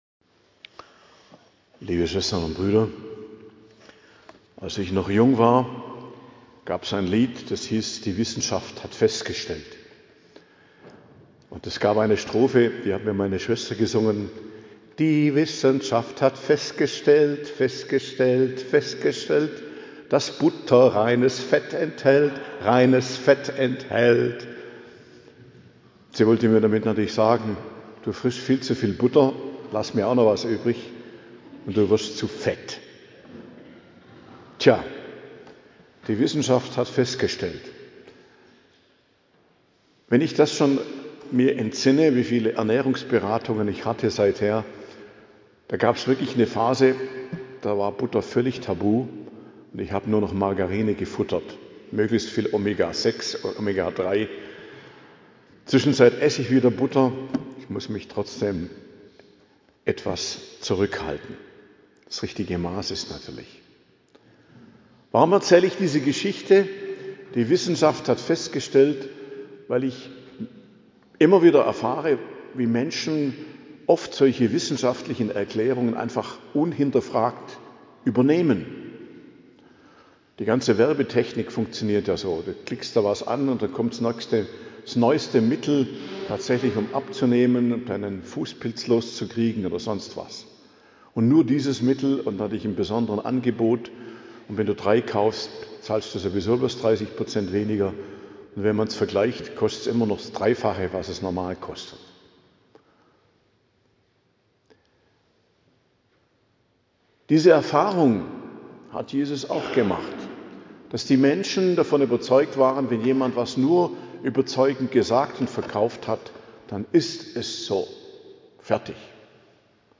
Predigt zum 6. Sonntag i.J., 15.02.2026 ~ Geistliches Zentrum Kloster Heiligkreuztal Podcast